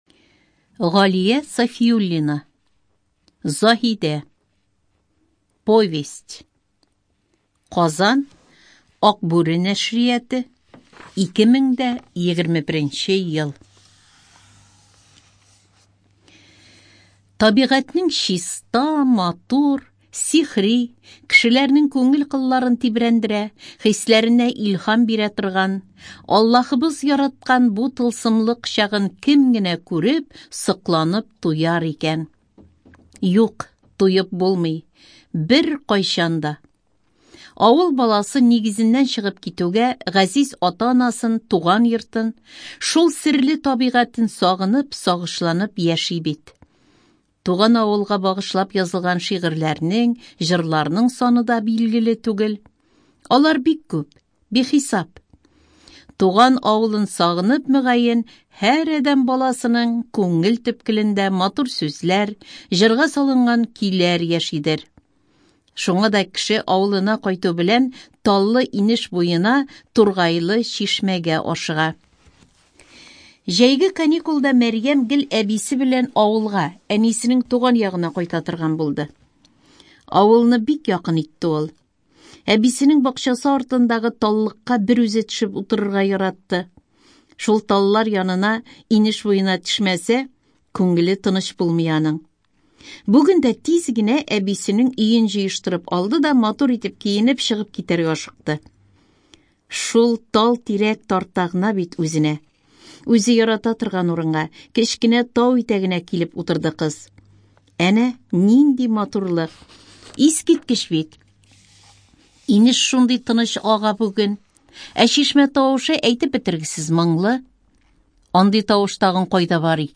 Студия звукозаписиТатарская республиканская специальная библиотека для слепых и слабовидящих